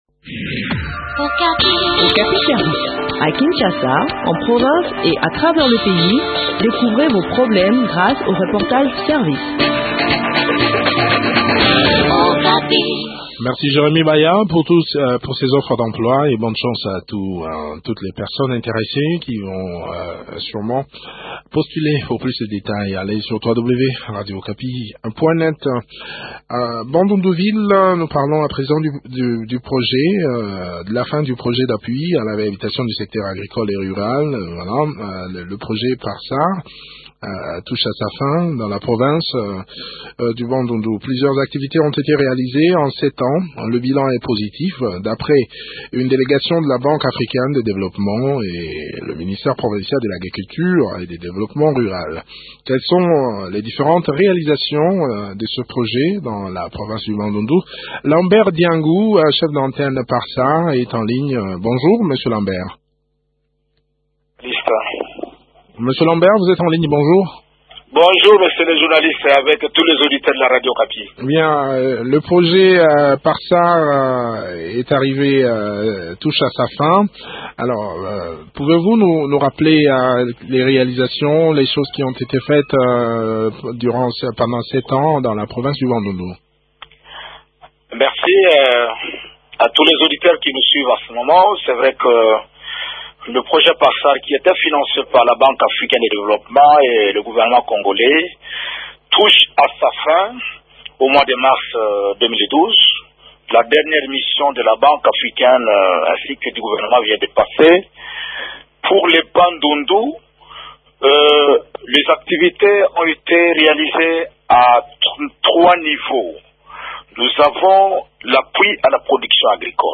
Le point sur l’exécution de ce projet dans cet entretien